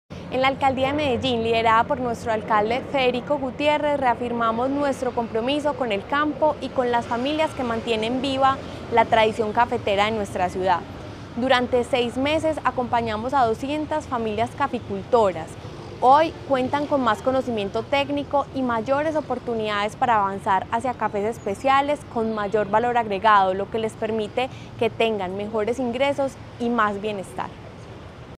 Declaraciones-secretaria-de-Desarrollo-Economico-Maria-Fernanda-Galeano-Rojo-2.mp3